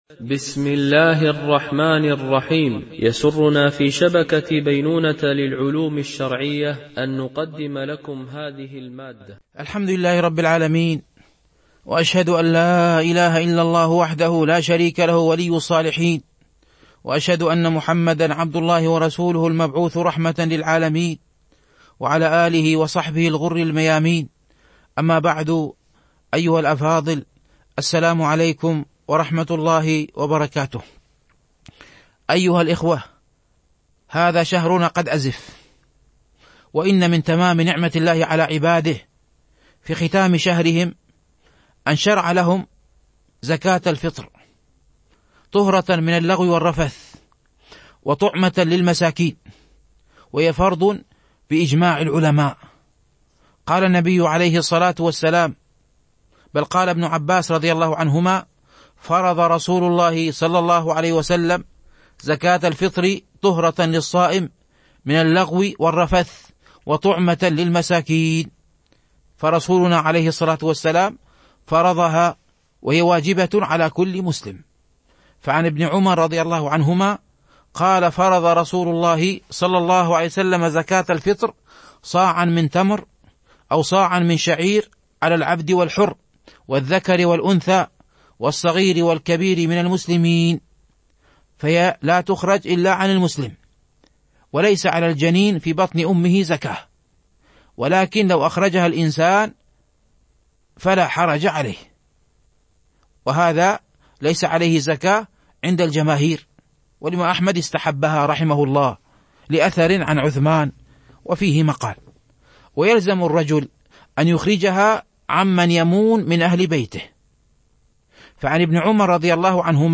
فقه الصائم - الدرس 27